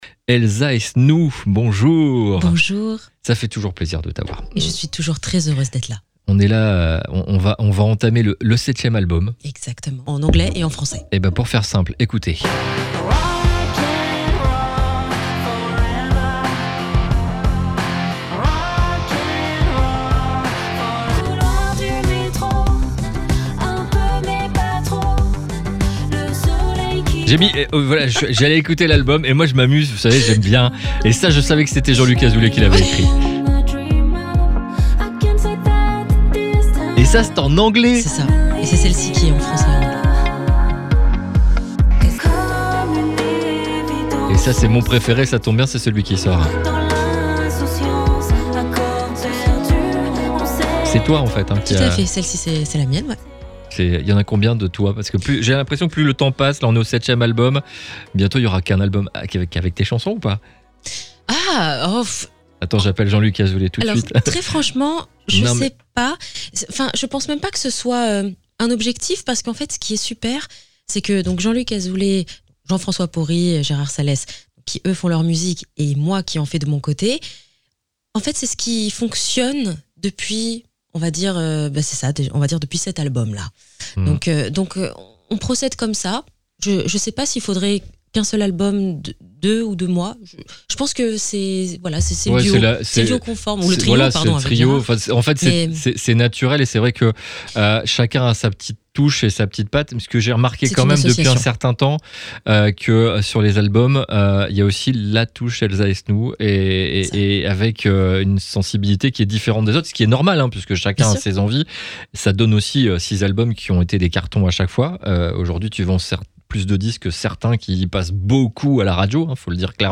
ELSA ESNOULT nous accorde une interview pour son nouvel album 7 qui sort en fevrier